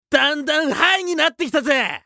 熱血系ボイス～戦闘ボイス～
【トランス状態1】